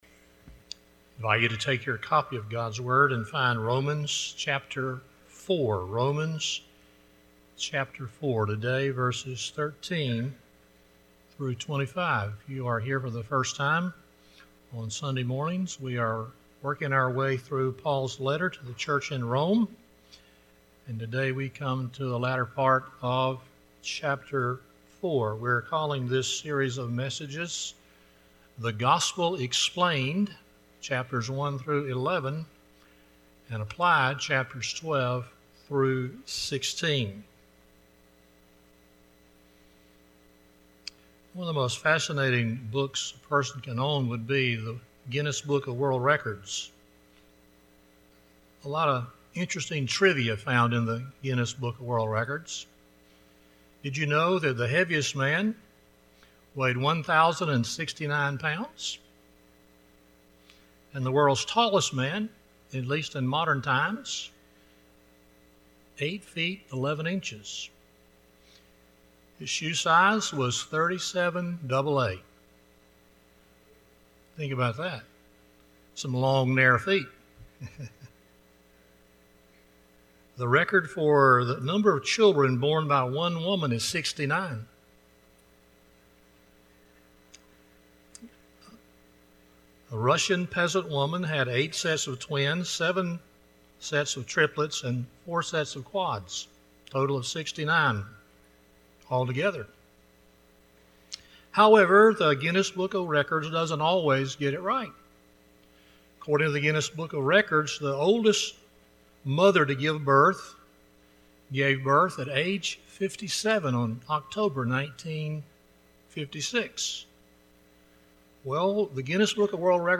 Romans 4:13-25 Service Type: Sunday Morning 1.